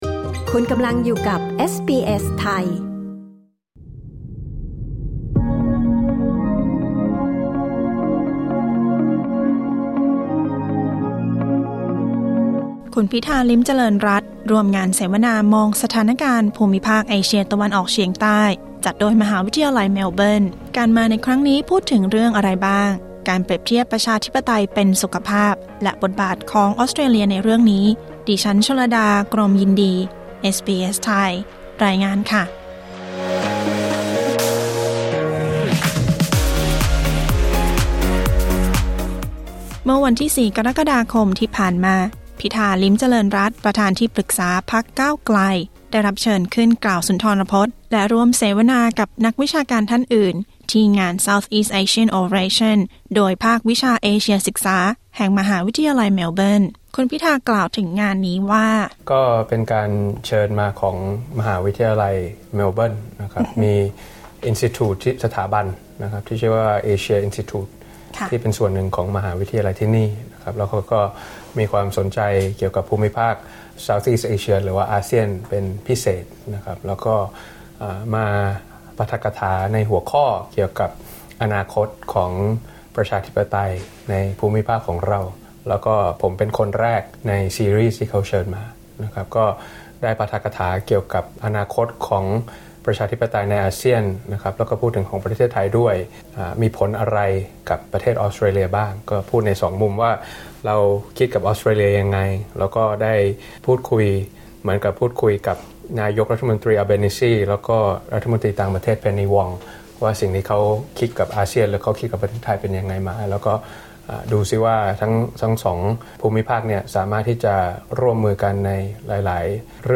กด ▶ ฟังสัมภาษณ์ฉบับเต็ม